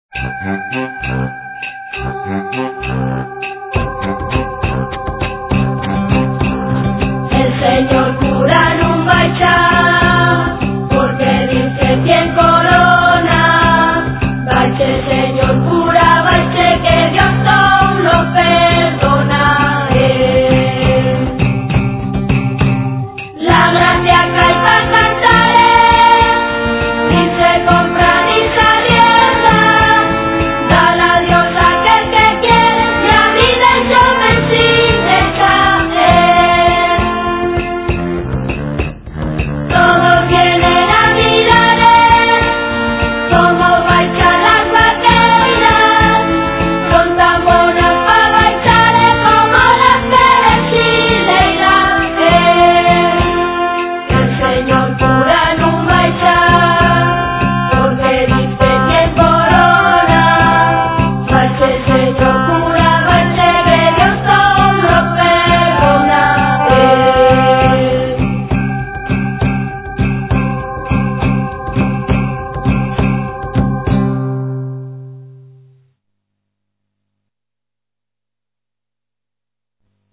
De xuru,que la lletra conozla fasta la vuesa güela porque son cancios de la tradición musical asturiana recoyíos munchos d'ellos del cancioneru musical d'Eduardo Martínez Torner.
Los que canten son los neños de los colexos públicos de Lieres y Sariegu y pa que-yos salga tan bien tienen qu'ensayar dos hores a la selmana
vaqueiras.mp3